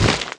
IMPACT2.WAV